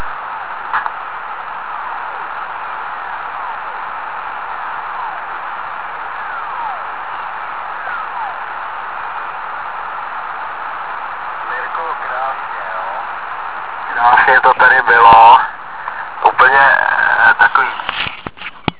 Oba signály pochází z pásma 80m a překlenutá vzdálenost byla 202 km.